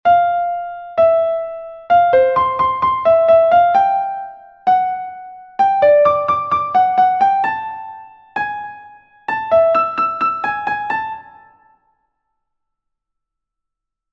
Rosalie oder Schusterfleck, ein kleiner, stufenweise wiederkehrender Satz (musikalische Phrase), der von den Theoretikern als Fehler gerügt wird, zum Beispiel: